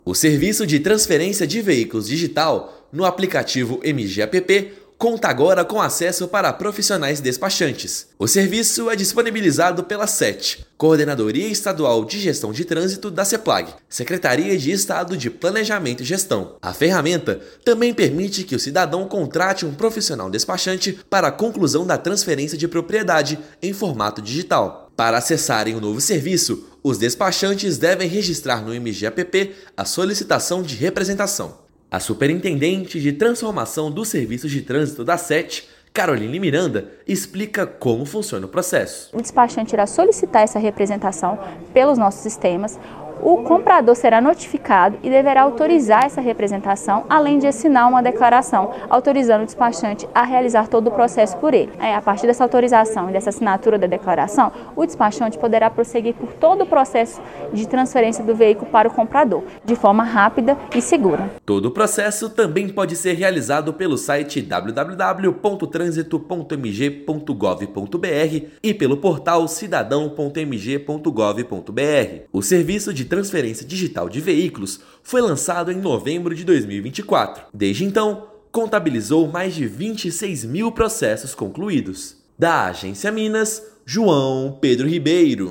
Nova funcionalidade do MG App permite o registro de representação para que despachante conclua o serviço digital. Ouça matéria de rádio.